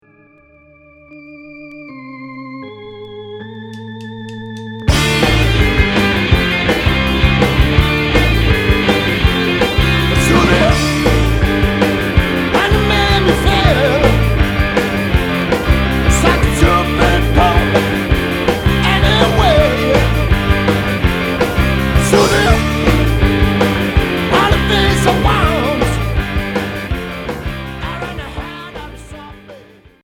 Garage Premier 45t retour à l'accueil